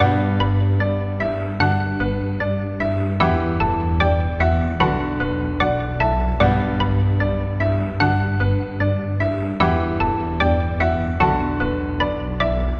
描述：有老Russ的影响，也有一些Juice Wrld的影响。
Tag: 150 bpm Trap Loops Piano Loops 2.15 MB wav Key : G